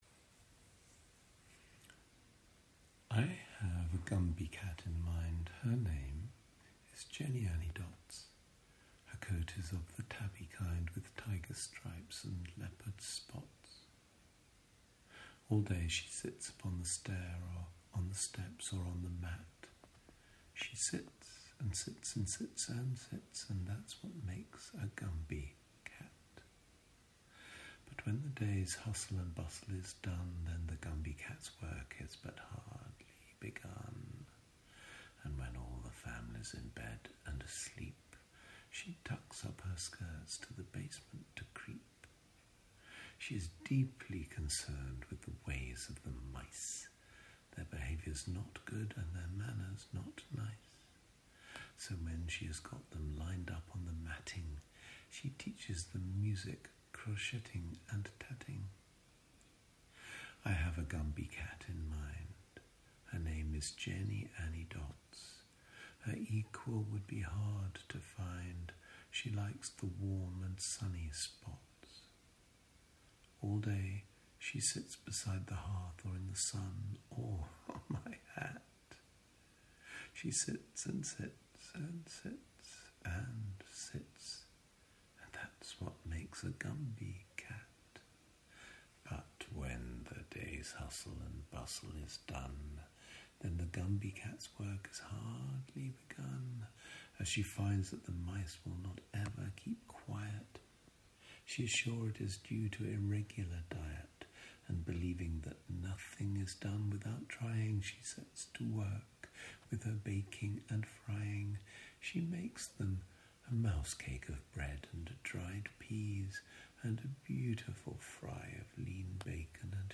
A reading